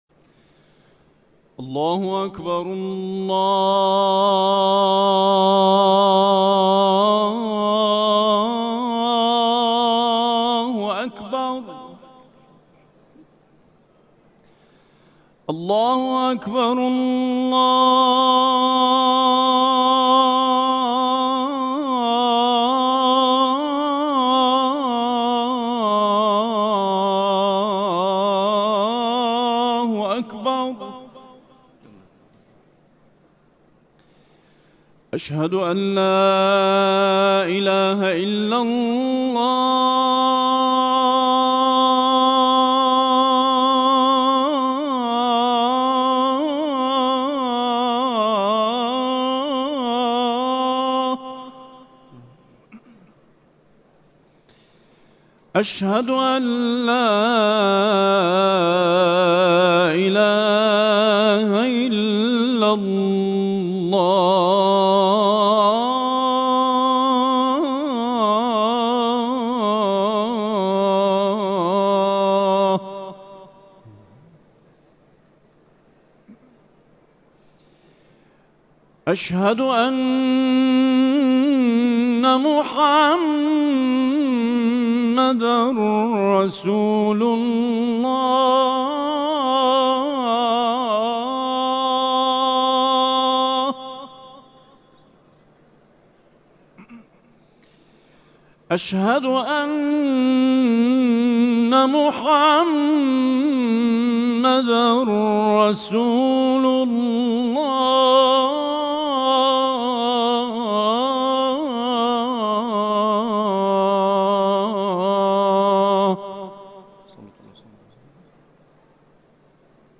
گروه فعالیتهای قرآنی: فرازهای صوتی دلنشین با صوت قاریان ممتاز و بین‌المللی قرآن که طی این روزها در شبکه‌های اجتماعی منتشر شده است، ارائه می‌شود.